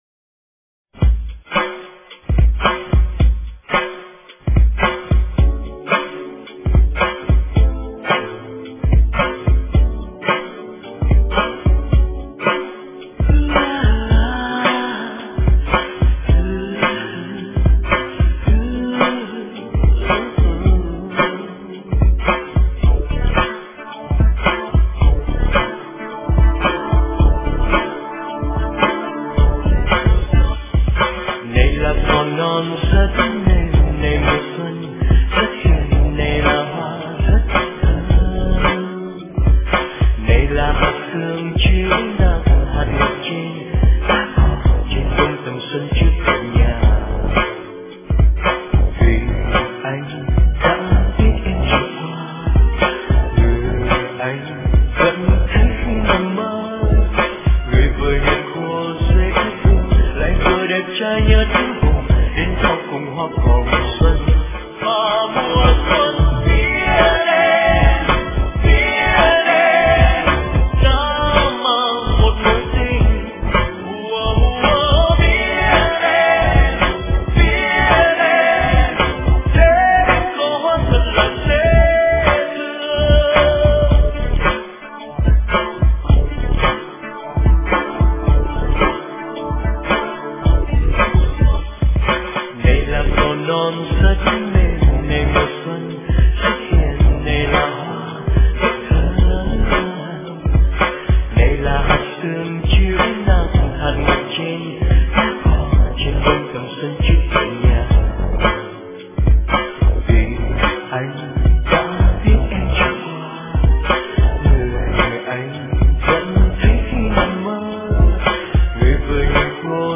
* Thể loại: Xuân